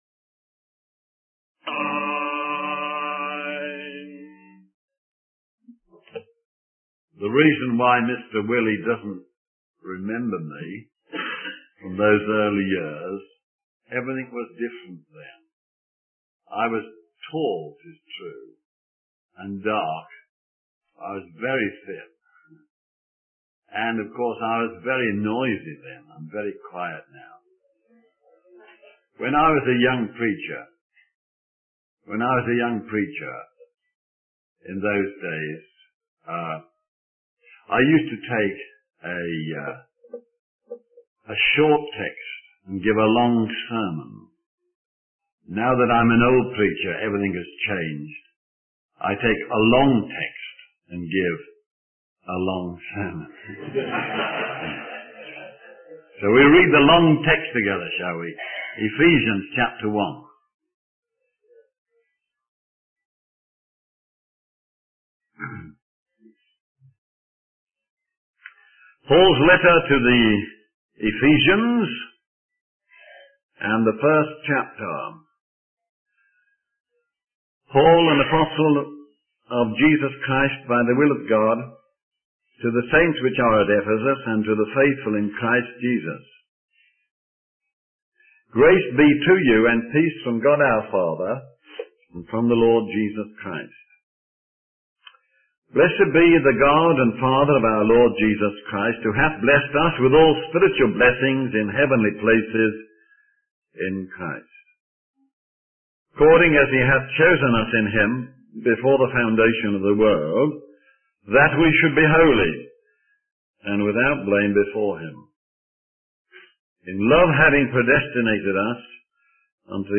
In this sermon, the preacher begins by acknowledging the noise outside the chapel and expresses gratitude for the strong construction of the building. He then highlights the main theme of the sermon, which is the believer's union with Christ. The preacher explains that being in Christ brings blessings, holiness, and acceptance.